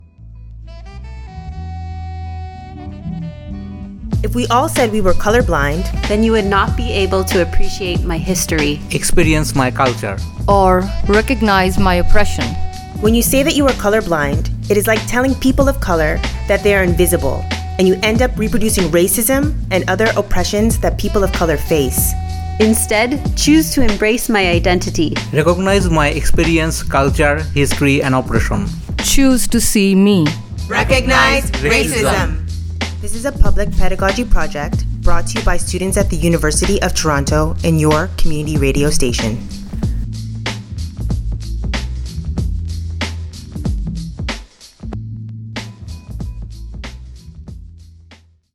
A public service announcement about how recognizing the experiences of racialized people.
Recording Location: Toronto
Type: PSA
128kbps Stereo